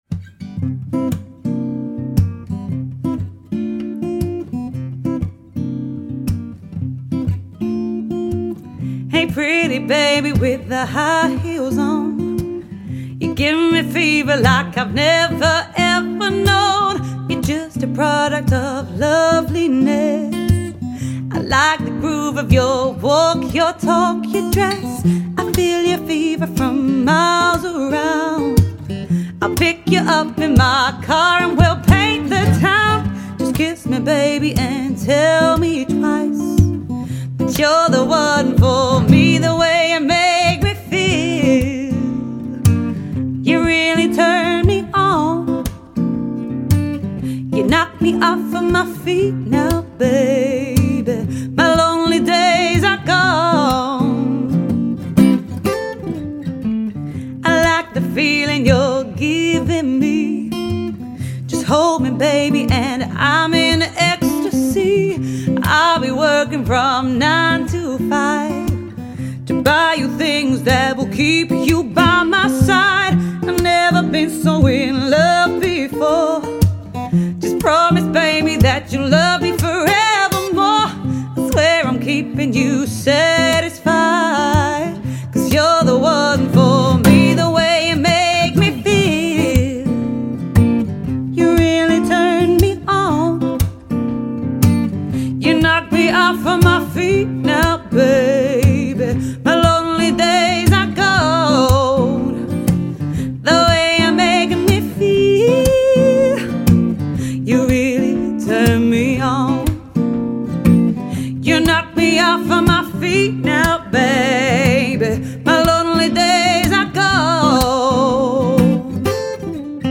Female Vocals, Acoustic Guitar